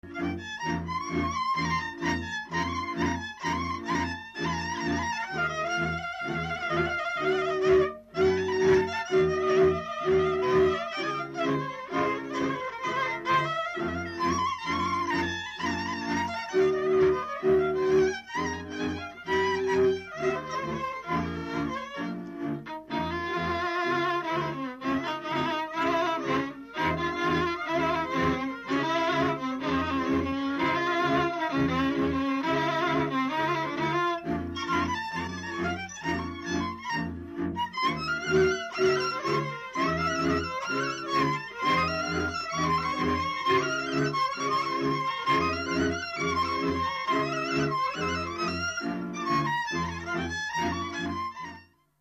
Dallampélda: Hangszeres felvétel
Erdély - Kolozs vm. - Magyarpalatka
hegedű
brácsa
bőgő
Stílus: 1.1. Ereszkedő kvintváltó pentaton dallamok